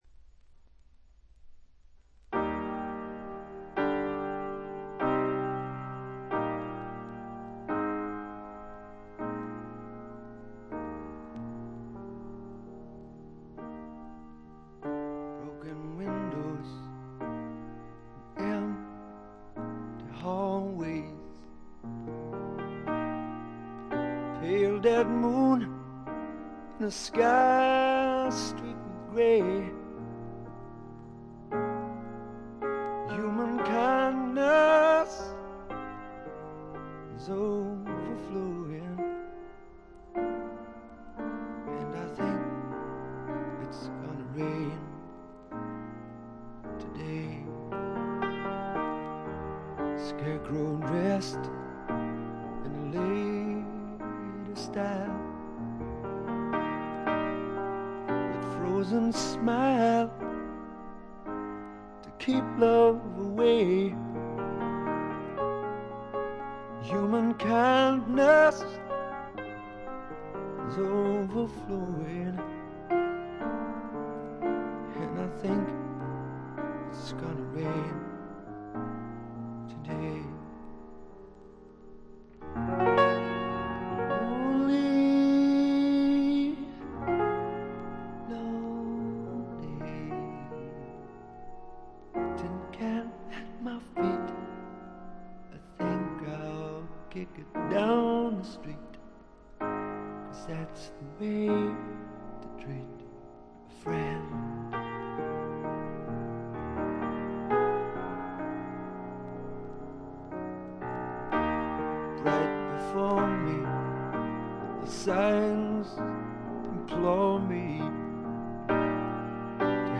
ちょいと鼻にかかった味わい深いヴォーカルがまた最高です。
試聴曲は現品からの取り込み音源です。